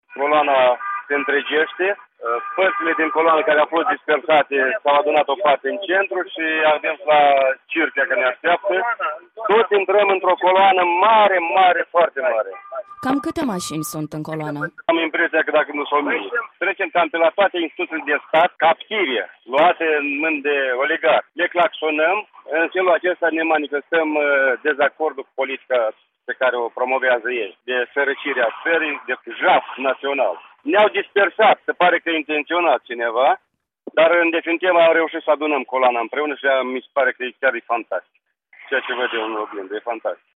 în timpul protestului automobiliștilor